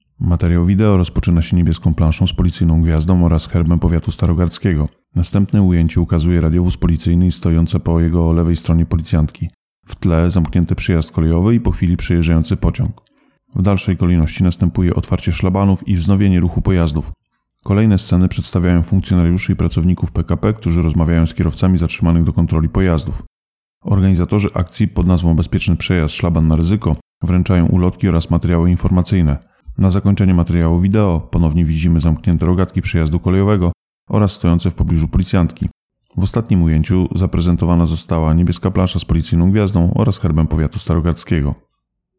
Nagranie audio 2022_08_05_-_Audiodeskrypcja_-_Bezpieczny_przejazd___Szlaban_na_ryzyko__Kampania_spoleczna_PKP_i_Policji.mp3